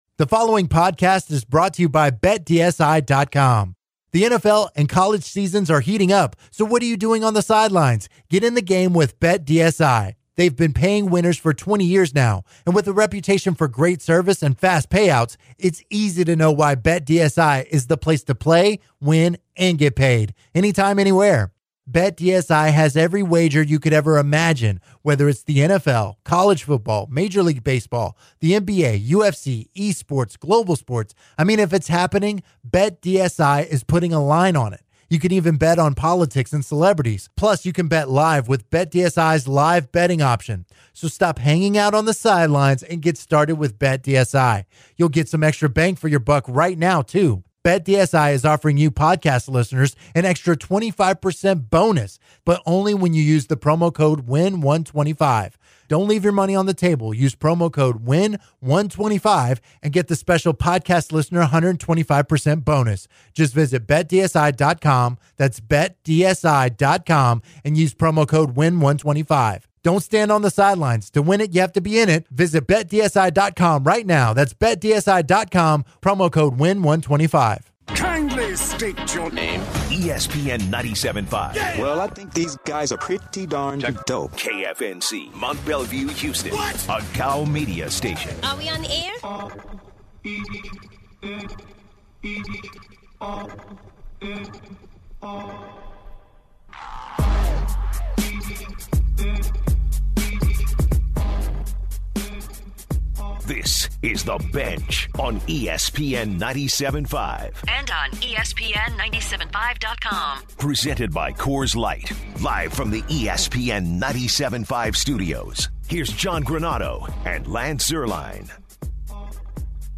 The guys also give their predictions on the NFL conference championship with the Rams versus Saints and Patriots versus Chiefs. They take calls from listeners on UH basketball making the NCAA sweet sixteen and the game between the Patriots and Chiefs. The guys close the hour discussing comments made by Celtics’ Kyrie Irving to the media about the team’s chemistry.